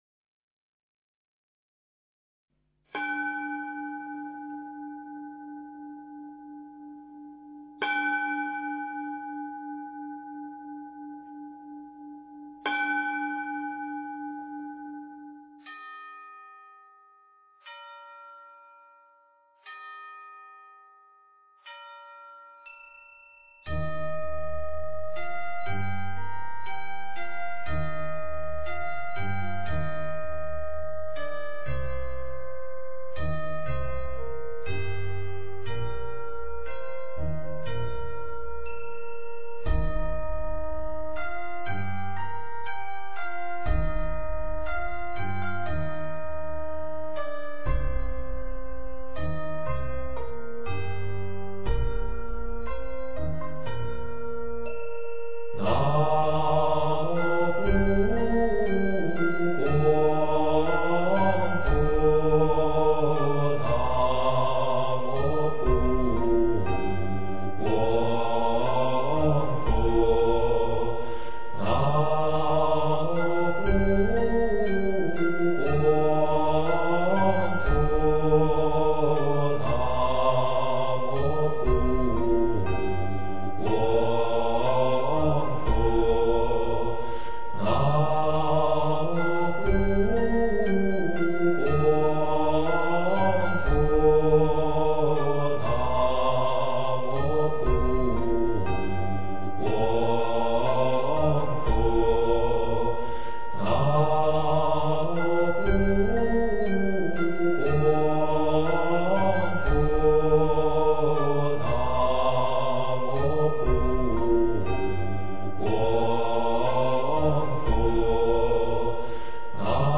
标签: 佛音 真言 佛教音乐